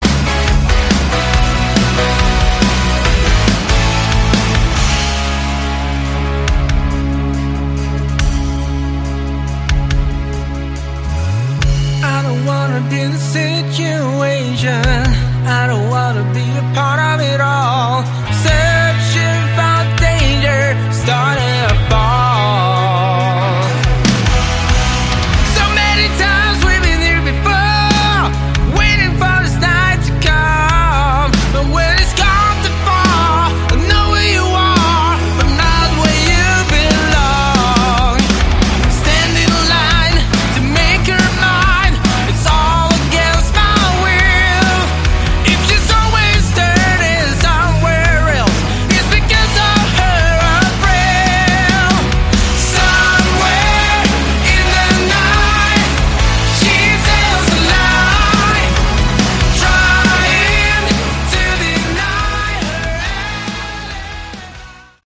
Category: Melodic Rock
lead vocals
guitars
drums, vocals